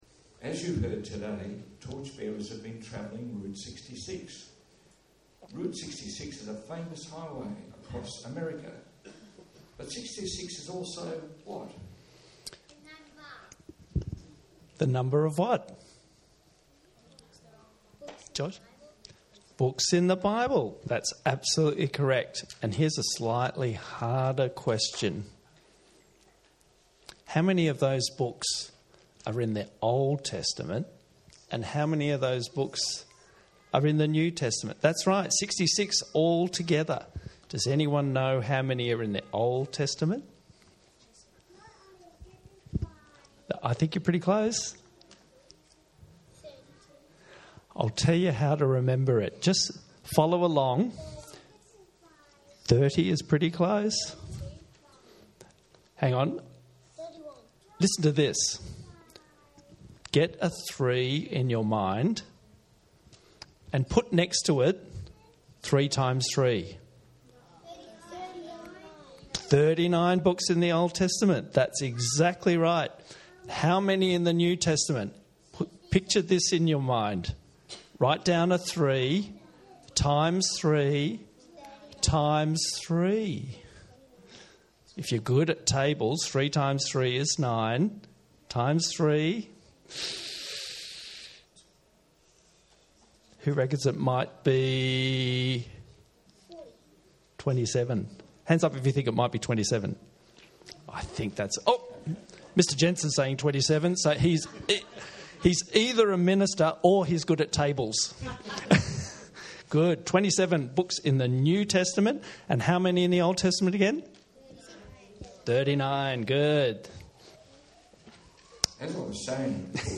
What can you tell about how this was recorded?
10am - Sunday School Service - St. Andrew's Presbyterian Church Clayton 10am-sunday-school-service